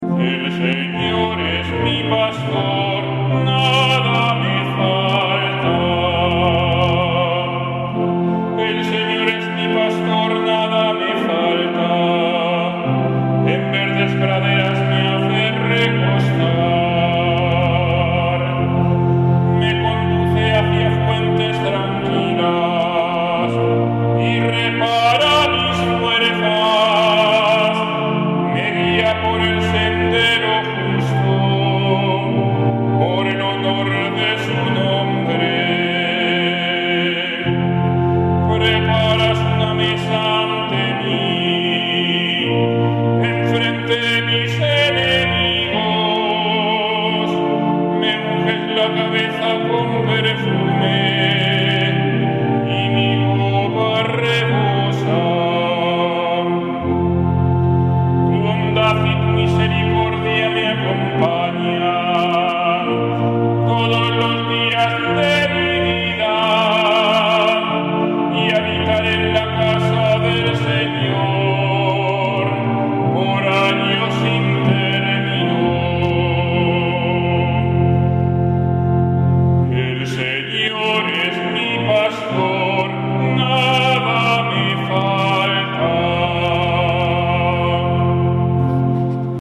Salmo Responsorial 22/1-3; 5-6